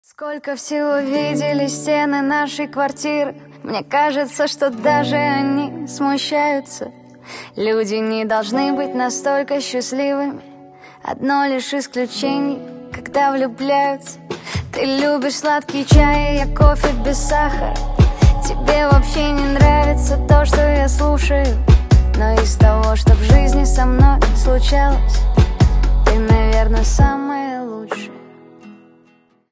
Поп Музыка
тихие # спокойные